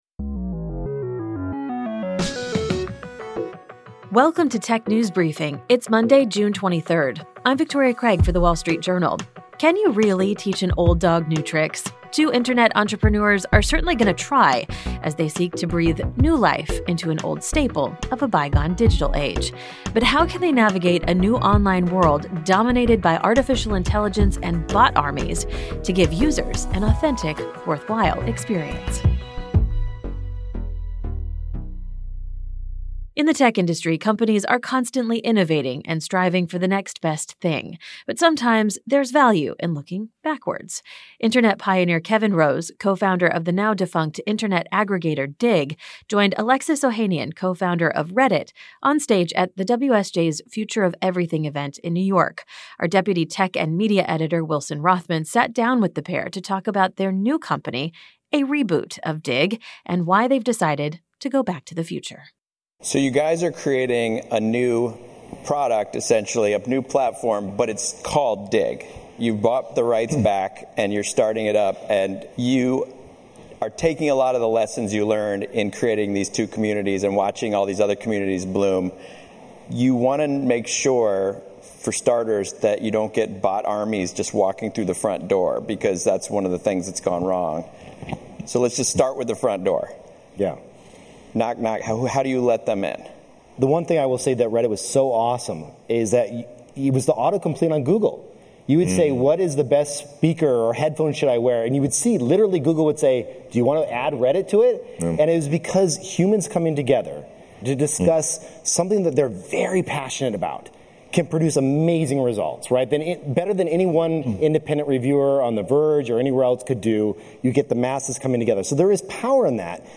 At least that’s what a pair of business partners are hoping when it comes to launching their next-generation Internet forum, a reboot of early internet aggregator Digg. Kevin Rose, co-founder of Digg, and Alexis Ohanian, co-founder of Reddit, discuss their plans on stage